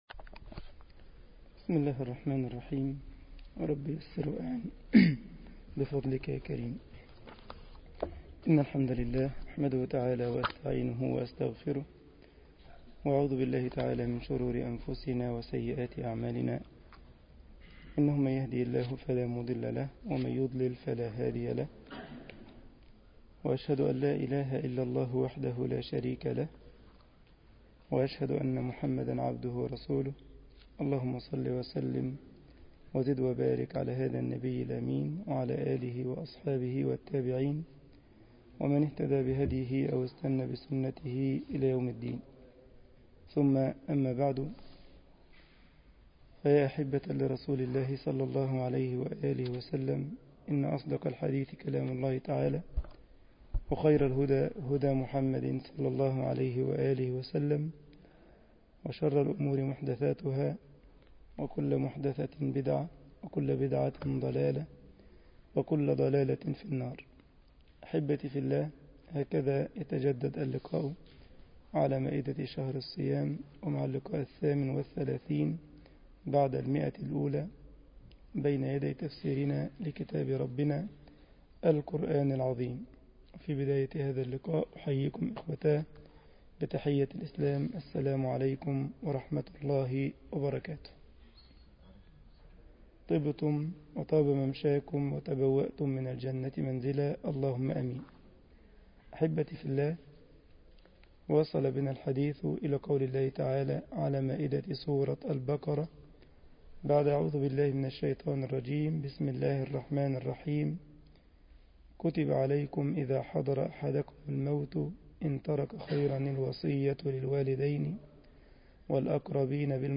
مسجد الجمعية الإسلامية بالسارلند ـ ألمانيا